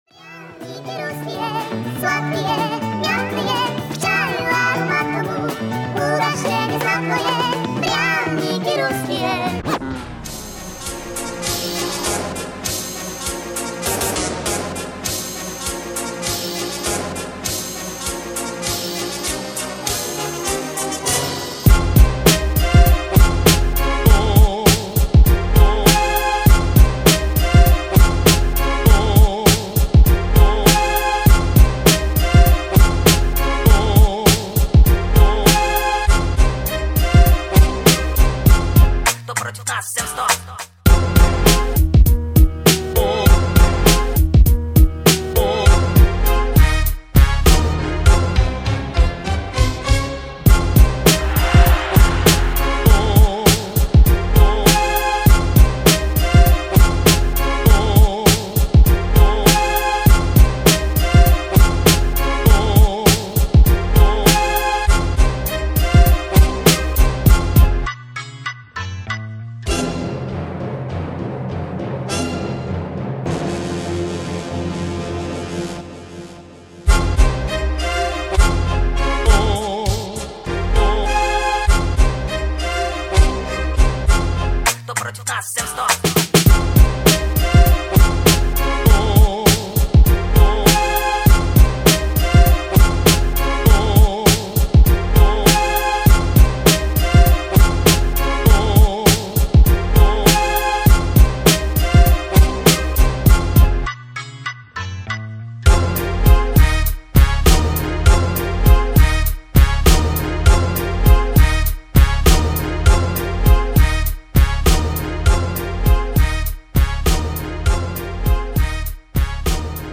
mp3,2930k] Рэп